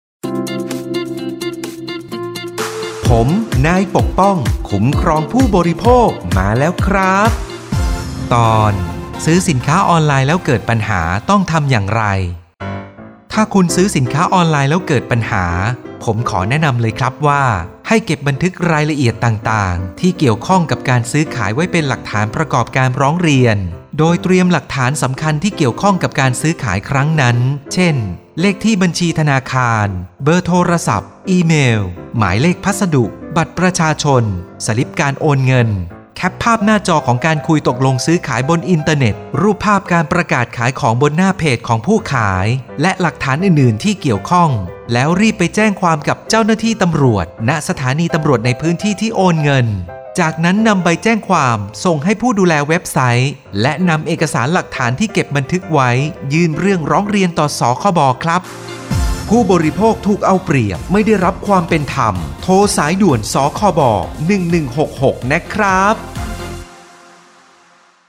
สื่อประชาสัมพันธ์ MP3สปอตวิทยุ ภาคกลาง
021.สปอตวิทยุ สคบ._ภาคกลาง_เรื่องที่ 21_.mp3